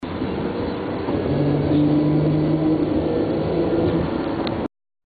Just ahead lies the Three Mile Slough bridge. Colloquially known as the "Lion Bridge", its graded steel-deck produces a lion-like "roar" when tires make contact. If this garners your interest, here's an MP3-capture of the crossing.